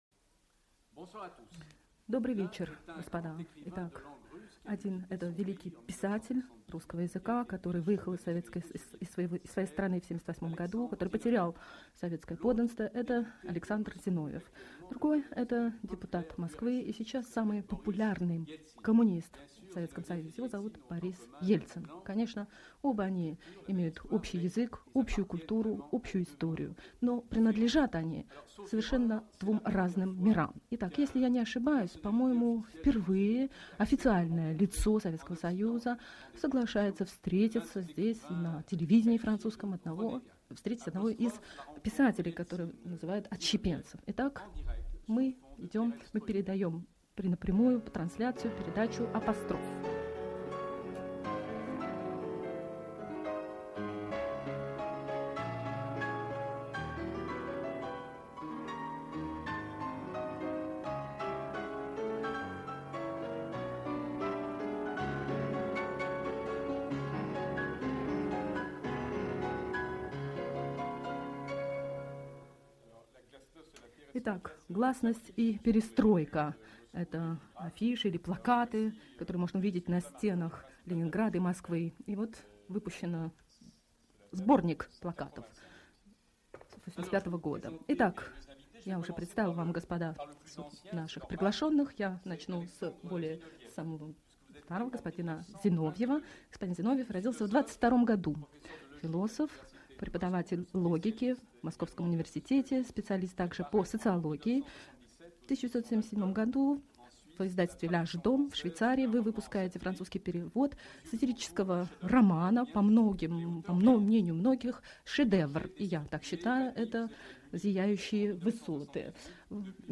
Александр Зиновьев и Борис Ельцин во французской программе «Апострофы». 1990 год. Уникальная передача, где социолог Александр Александрович Зиновьев делает максимально точный прогноз относительно развала СССР и предсказывает ГКЧП.